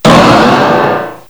cry_not_mega_latias.aif